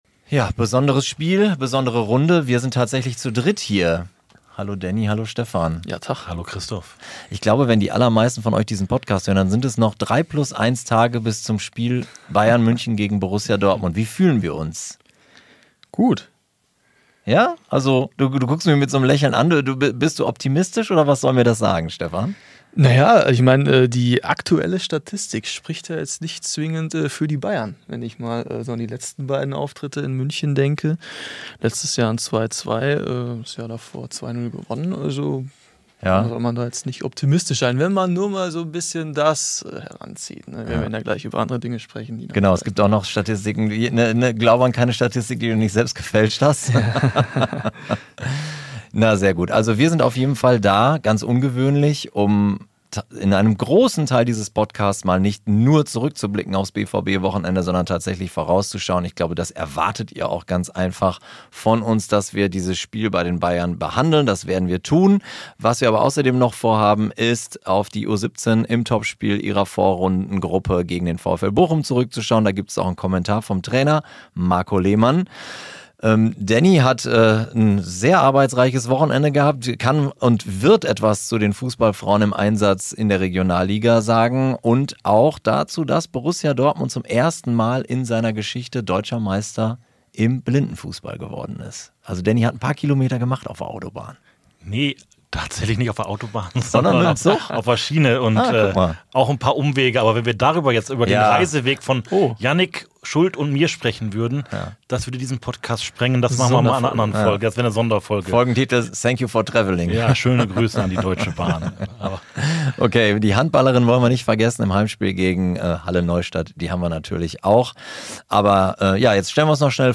haben sich extra zu dritt ins Podcaststudio gesetzt, um Statistiken auszuwerten und mögliche Aufstellungen zu diskutieren.